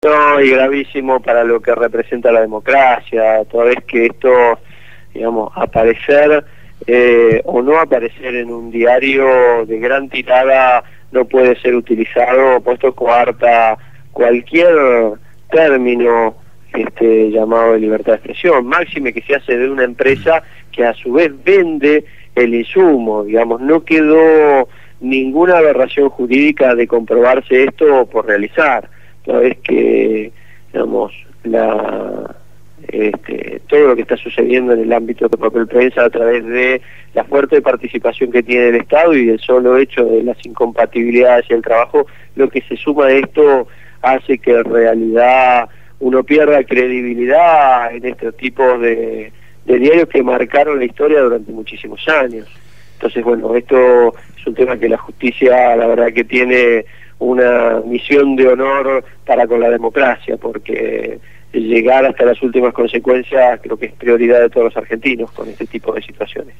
Daniel Reposo, titular de la SIGEN (Sindicatura General de la Nación) fue entrevistado